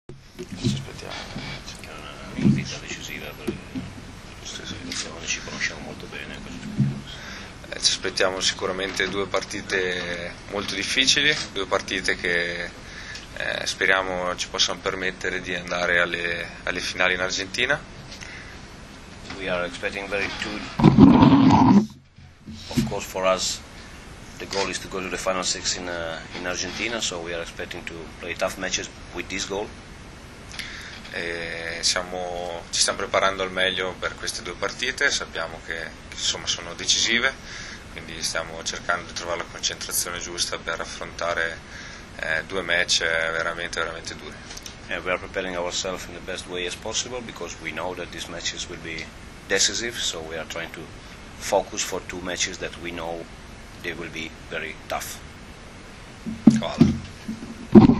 U hotelu Park u Novom Sadu danas je održana konferencija za novinare uoči utakmica poslednjeg, V V vikenda B grupe XXIV Svetske lige 2013. između Srbije i Italije.
IZJAVA KRISTIJANA SAVANIJA